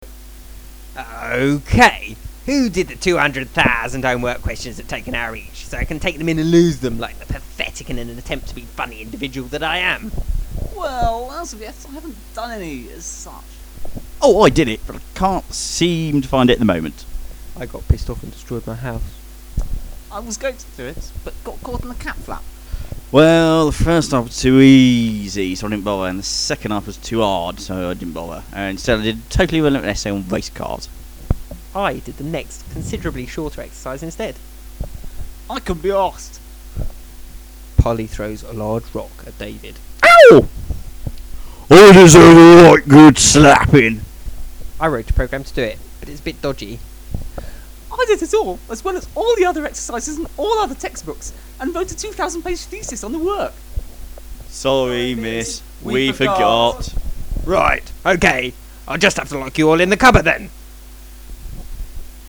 History / Maths lessons
We recorded the History and Maths lessons from the encyclopedia, each taking appropriate parts.